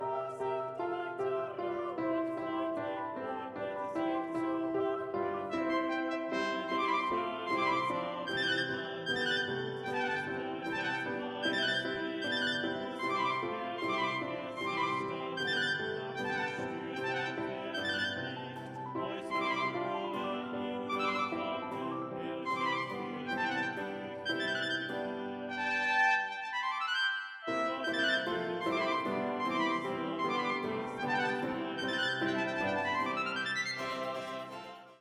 - Use one device to play the ACCOMPANIMENT ONLY (not vocal model) recording
Tenor/Bass Accompaniment Track